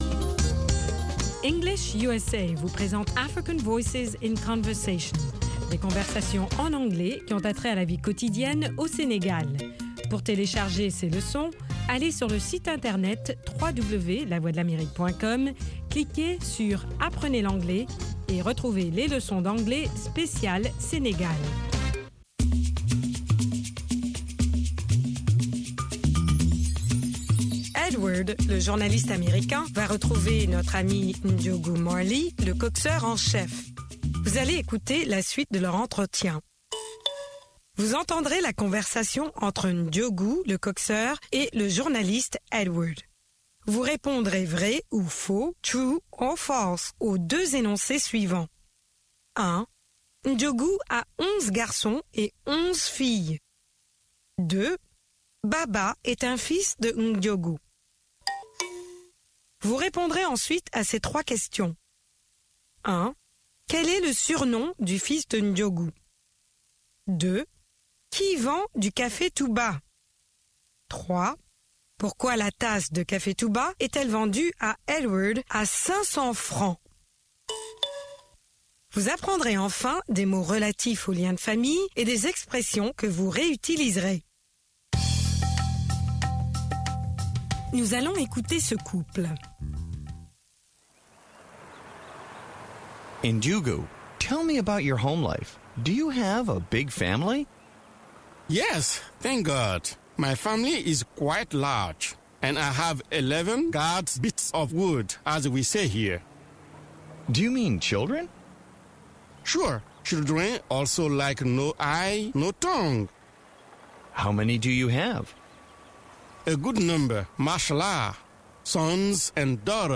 5 Min Newscast